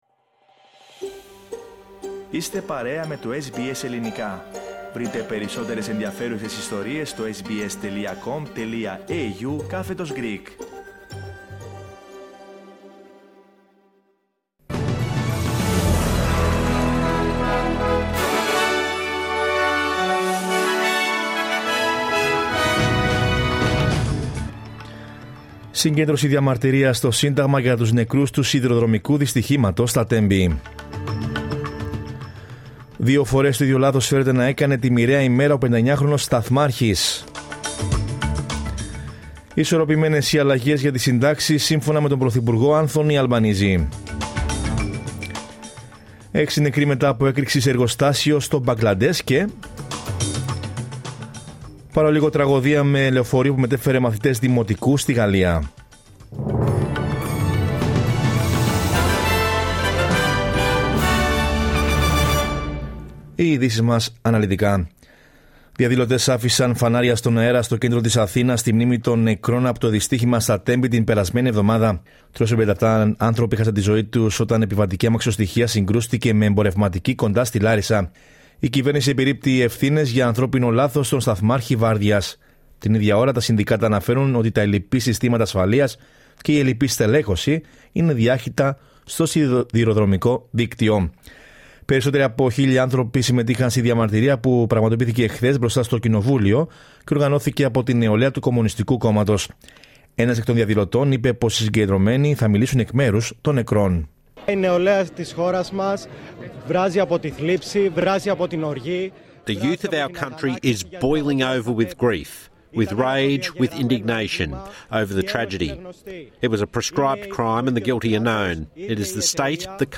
Sunday News Bulletin 05.03.23